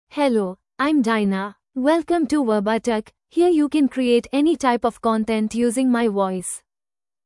FemaleEnglish (India)
Diana is a female AI voice for English (India).
Voice sample
Female
Diana delivers clear pronunciation with authentic India English intonation, making your content sound professionally produced.